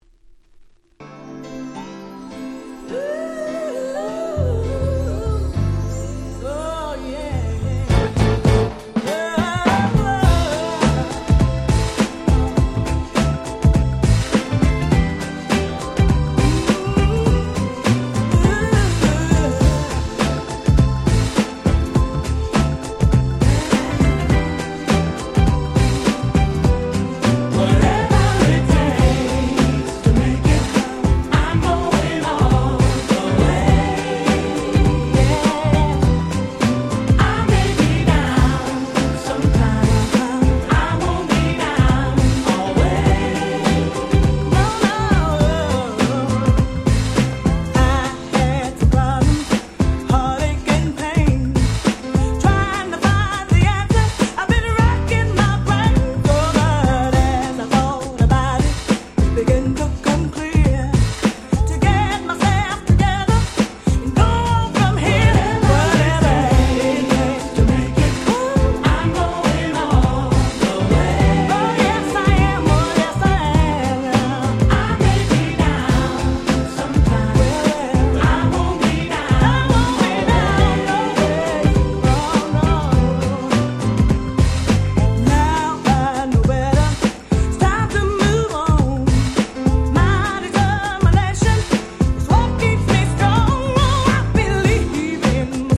94' Nice R&B EP !!